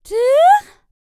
Index of /90_sSampleCDs/Spectrasonics Vocal Planet CD4 - R&B Dance/3 FEM. HUMAN